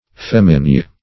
Search Result for " feminye" : The Collaborative International Dictionary of English v.0.48: Feminye \Fem"i*nye\, n. [OF. femenie, feminie, the female sex, realm of women.]